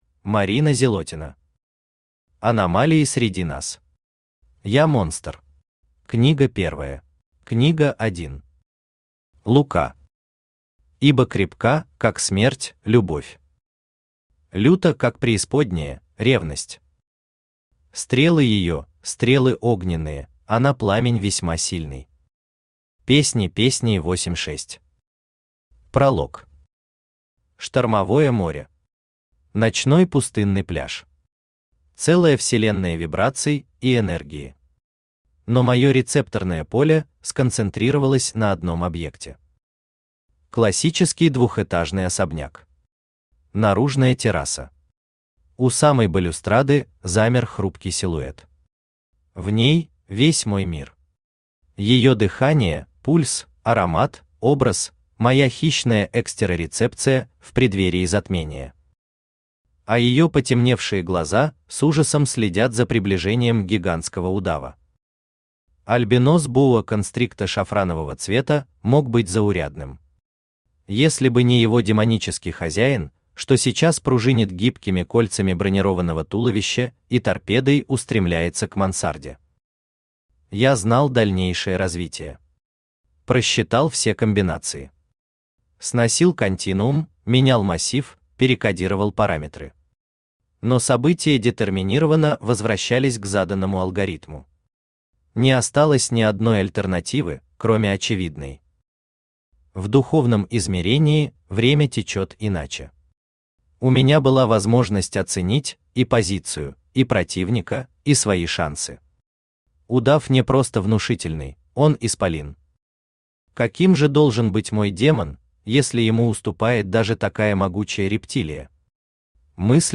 Аудиокнига Аномалии среди нас. Седовласый херувим. Я монстр | Библиотека аудиокниг
Я монстр Автор Марина Зилотина Читает аудиокнигу Авточтец ЛитРес.